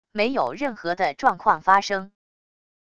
没有任何的状况发生wav音频生成系统WAV Audio Player